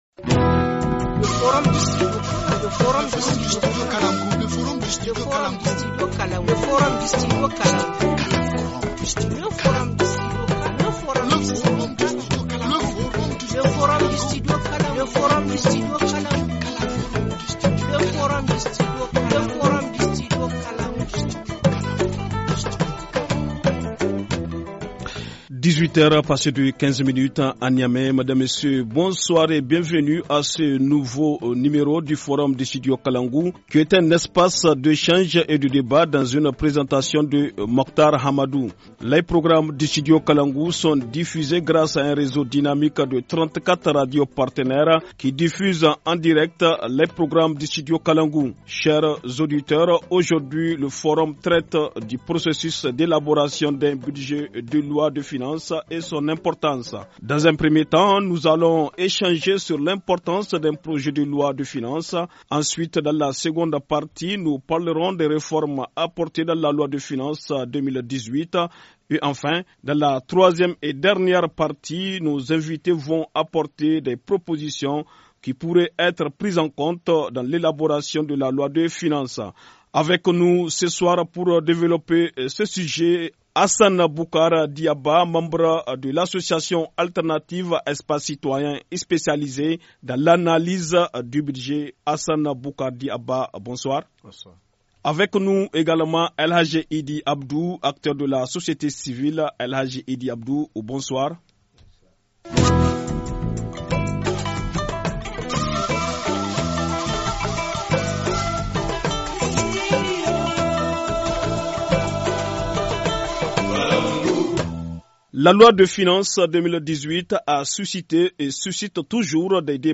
Forum 16/01/2018 : processus d’élaboration d’un projet de loi de finances et importance - Studio Kalangou - Au rythme du Niger